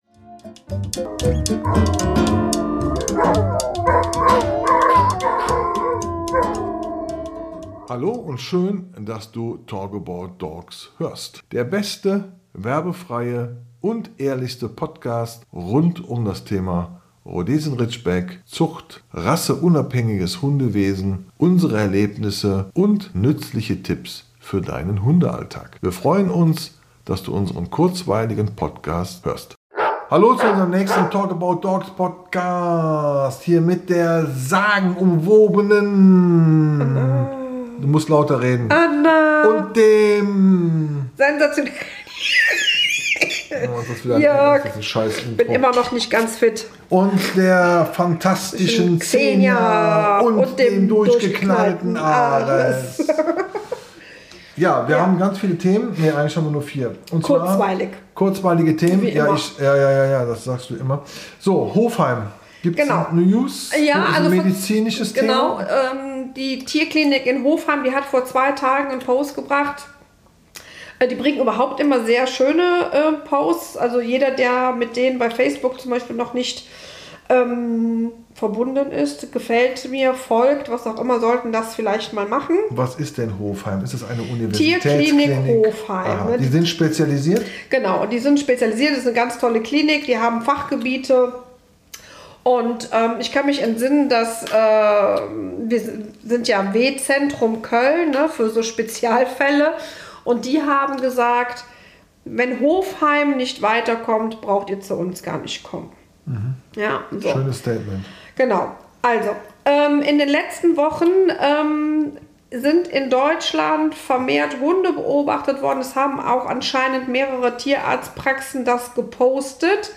Mit Gebell und Stimmen aus der Hundeszene gibt es besondere Einblicke zum Thema: Alltagssituationen, Kennel und Zucht, Reisepodcast sowie Welpenzauber.